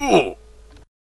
hurt.ogg